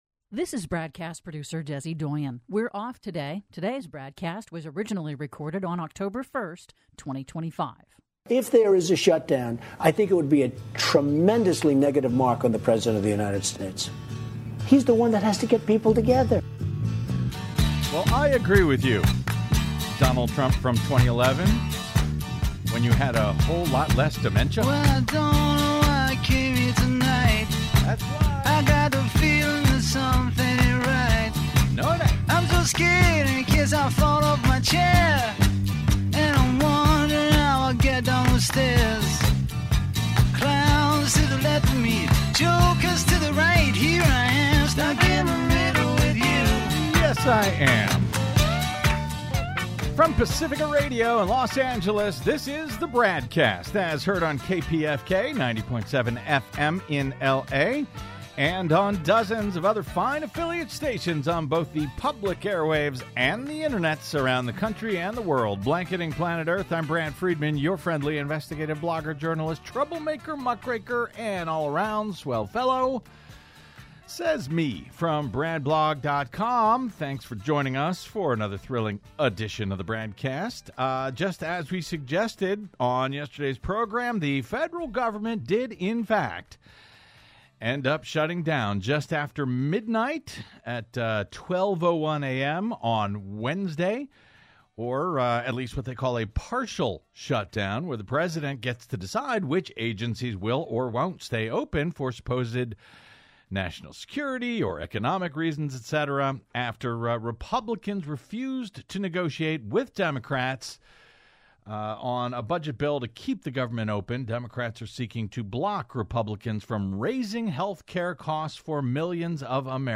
investigative interviews, analysis and commentary